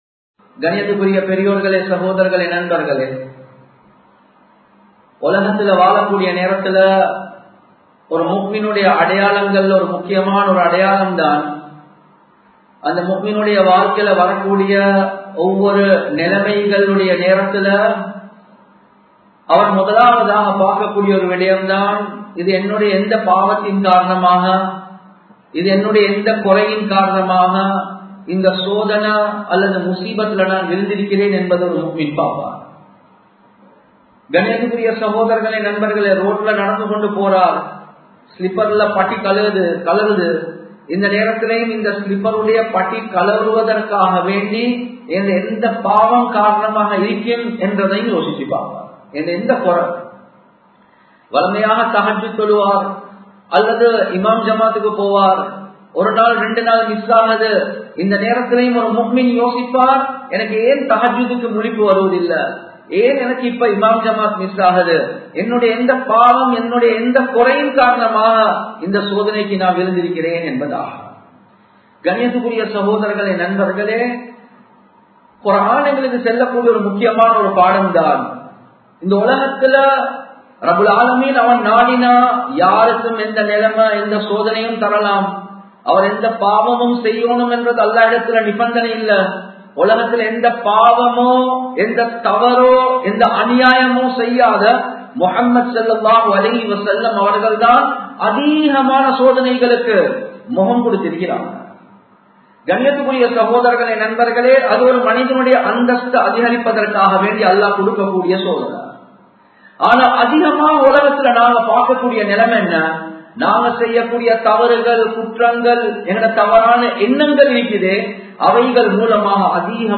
அநியாயத்தின் விளைவுகள் | Audio Bayans | All Ceylon Muslim Youth Community | Addalaichenai
Samman Kottu Jumua Masjith (Red Masjith)